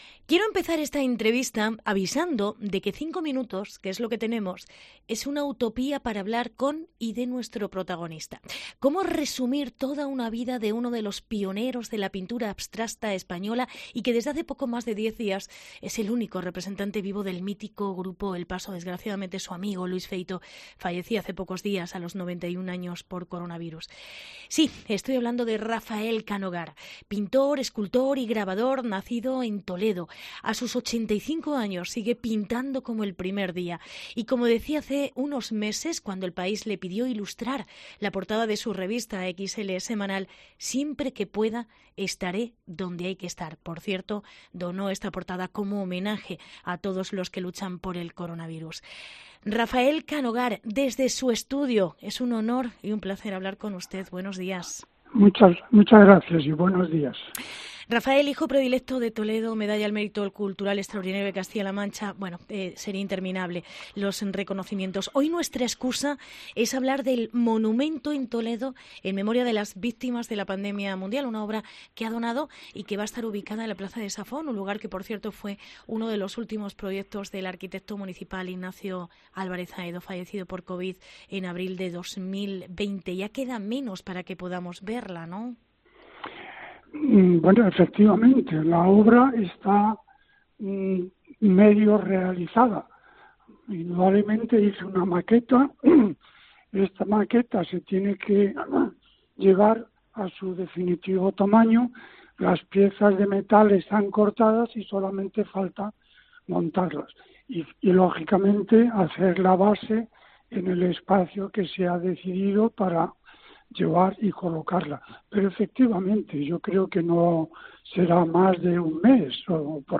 Entrevista con Rafael Canogar